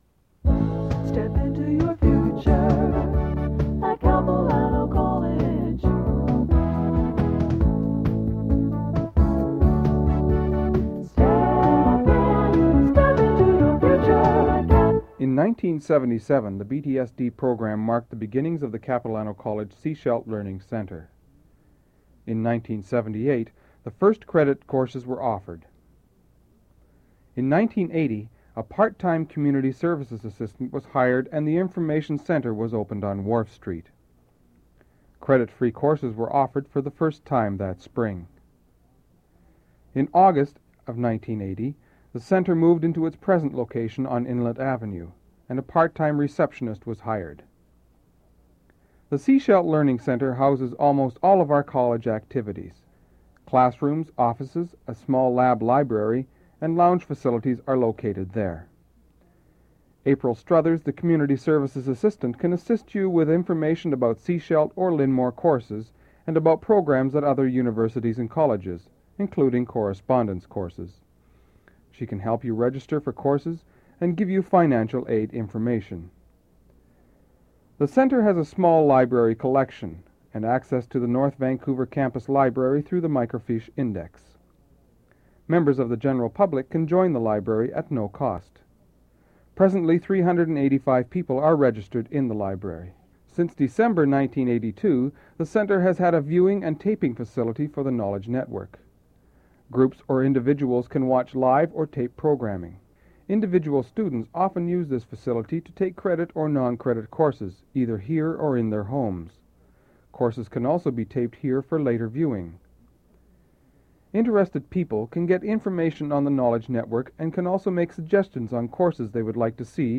Audio non-musical
Audio track with voiceover narrative and background music used to accompany slide show at recruiting presentations.
audio cassette